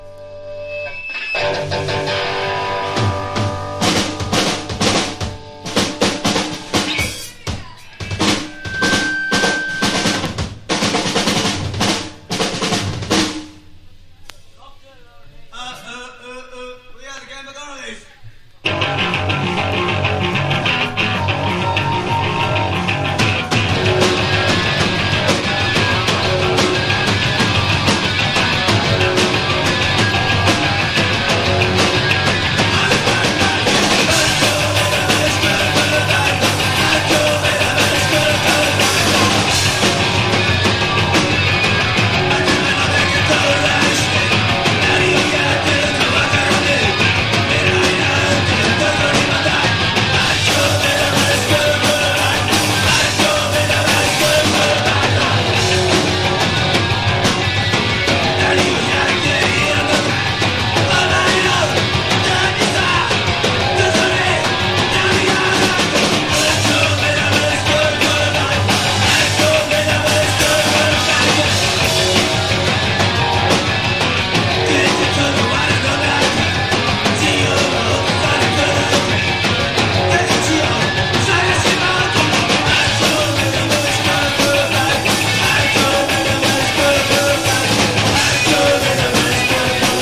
POPS# PUNK / HARDCORE